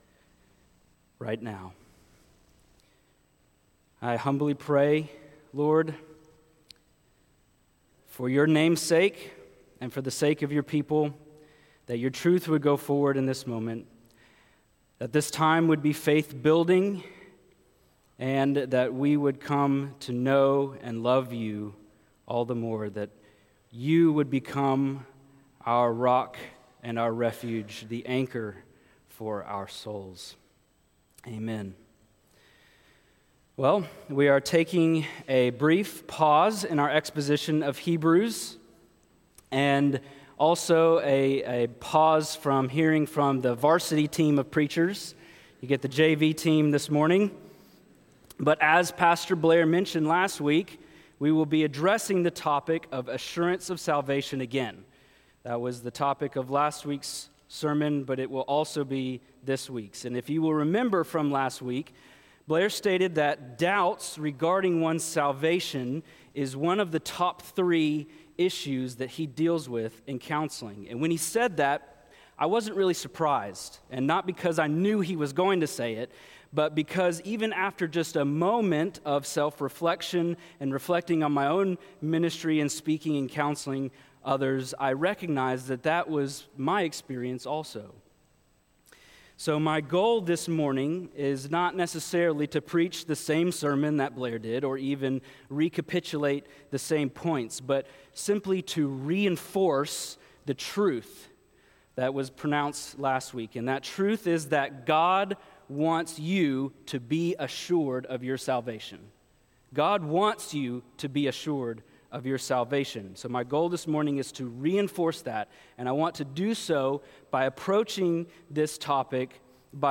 Weekly Sermons from Providence Baptist Church in Huntsville Alabama